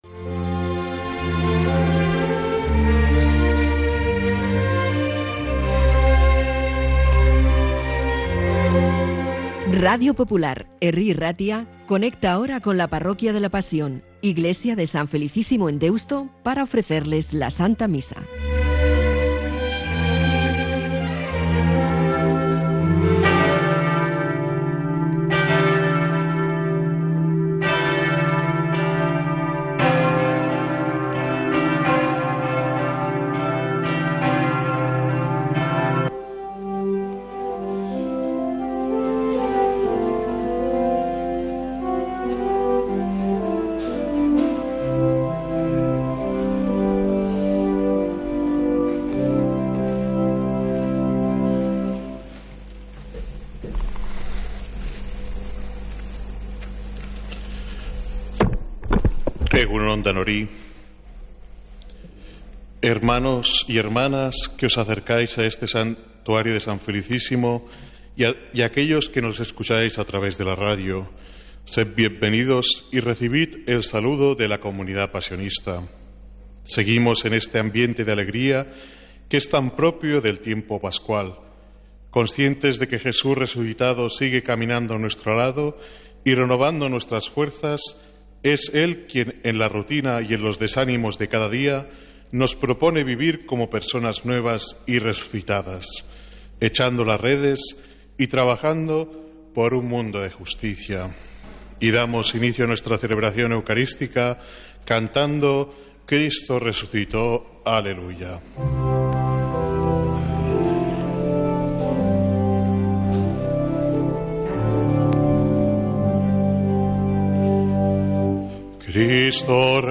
Santa Misa desde San Felicísimo en Deusto, domingo 4 de mayo